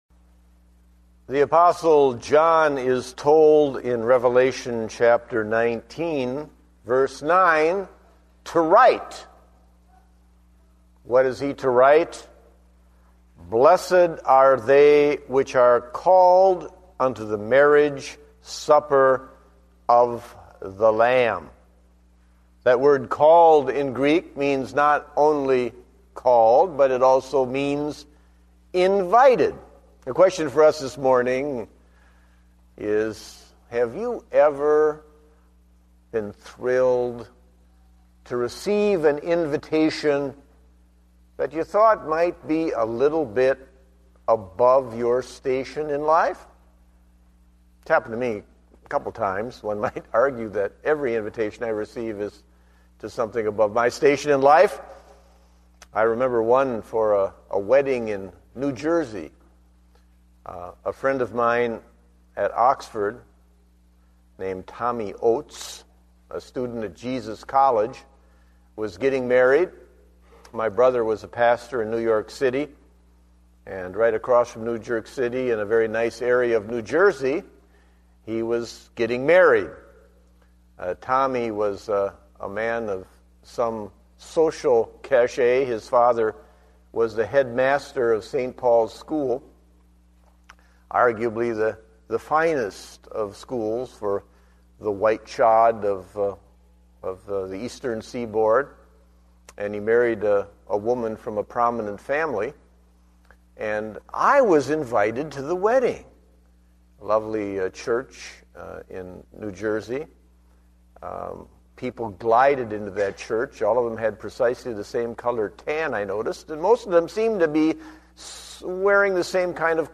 Date: January 3, 2010 (Morning Service)